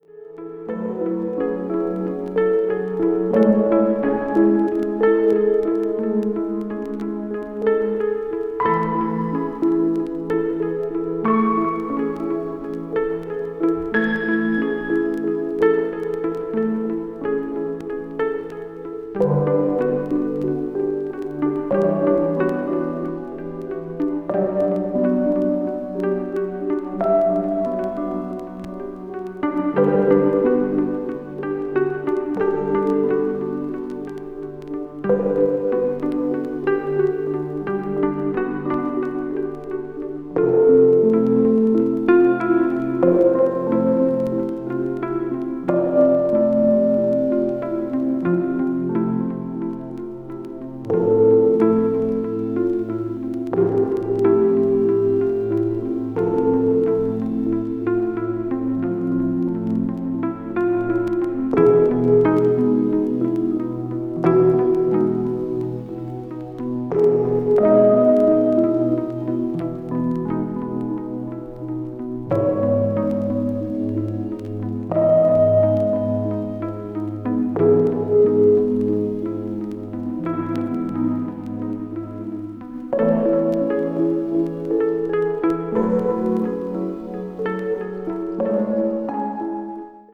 非常に音数の少ない淡々とミニマルにリフレインするシンセサイザーの旋律に小鳥のさえずりなどがトッピングされ